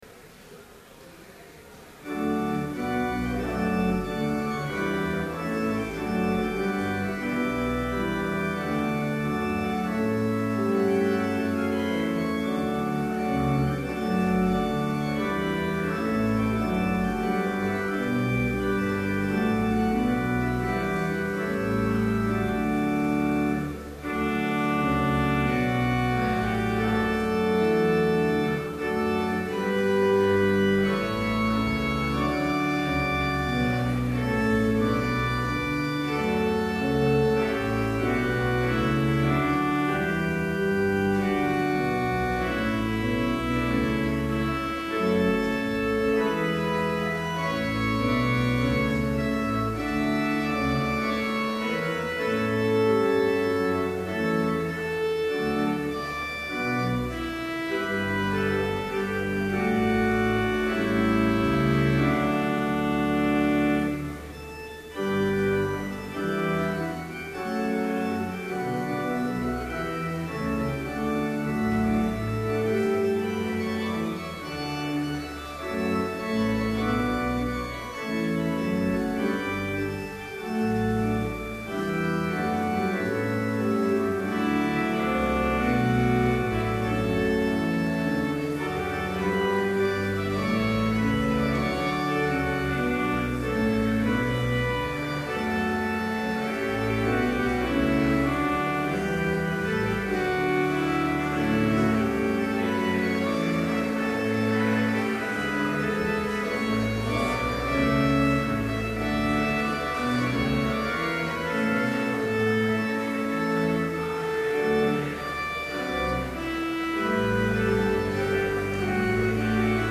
Complete service audio for Chapel - April 24, 2012